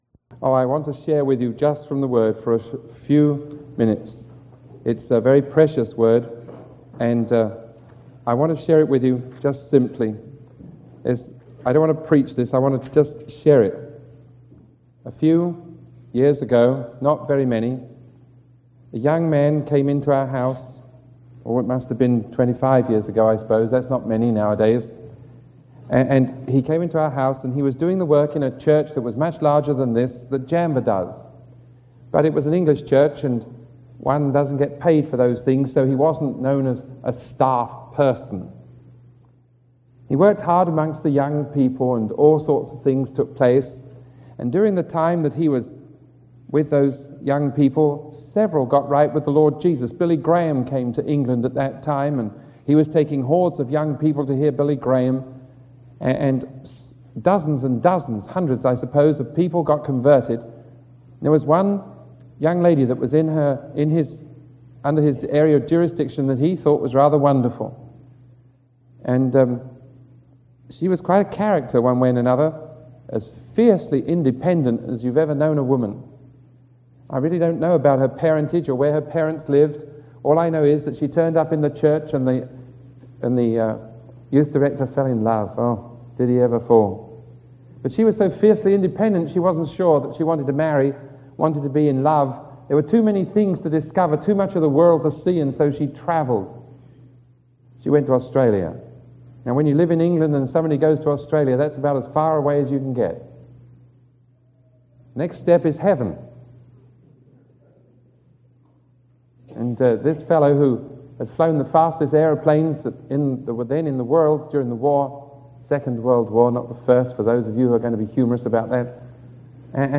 Sermon 1029A recorded on October 20